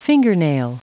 Prononciation du mot fingernail en anglais (fichier audio)
Prononciation du mot : fingernail
fingernail.wav